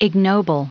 added pronounciation and merriam webster audio
977_ignoble.ogg